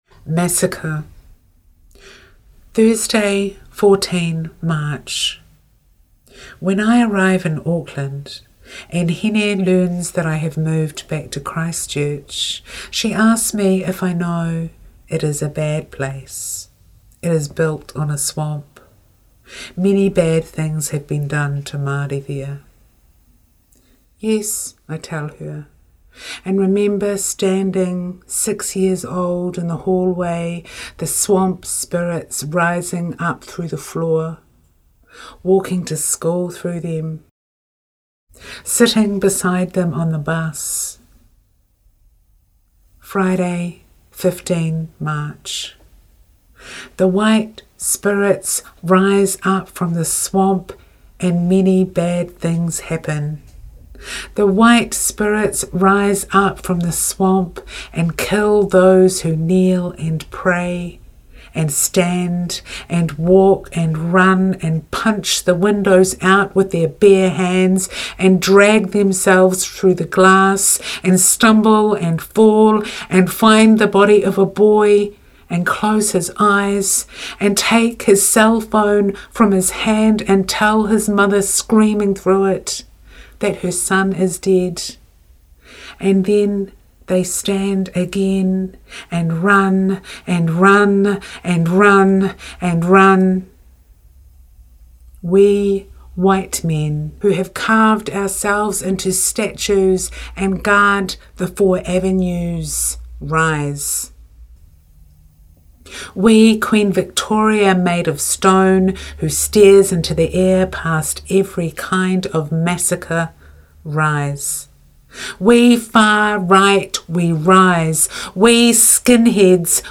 reads ‘Massacre’